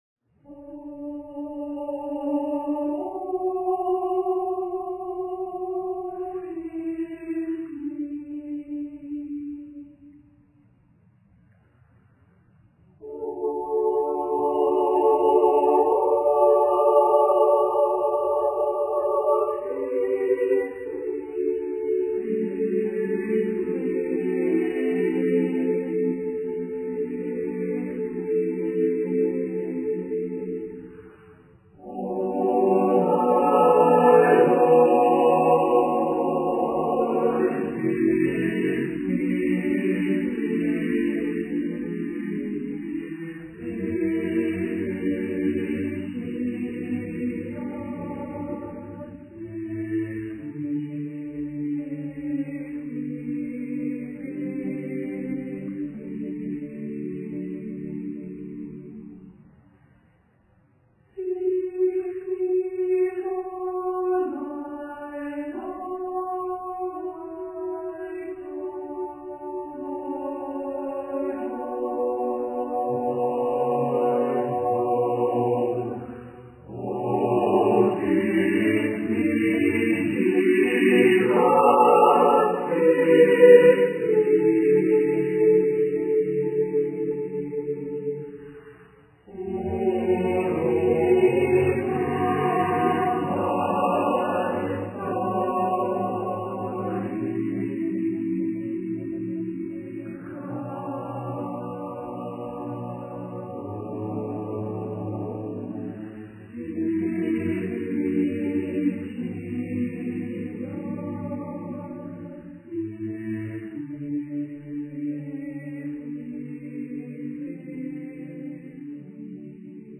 Accompaniment:      None
Music Category:      Choral